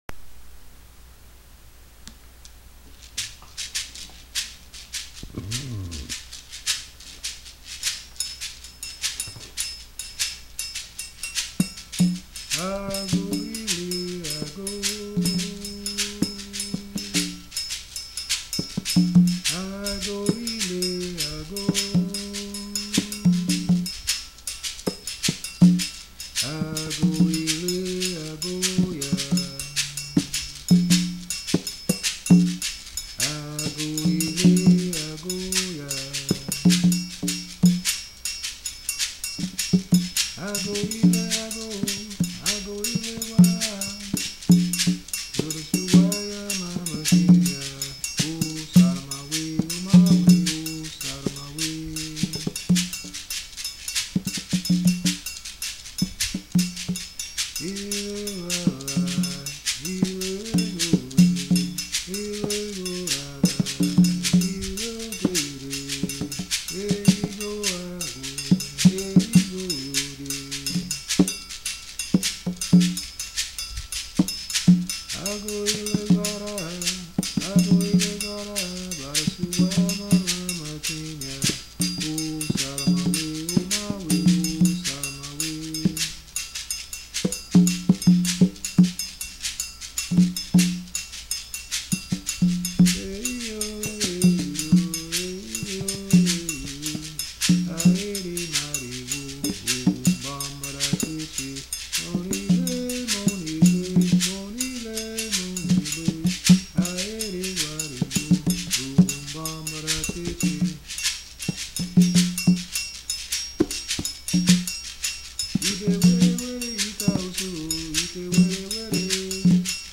Guiro
Description A guiro, with me playing all the parts.